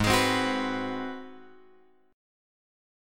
G# 7th Sharp 9th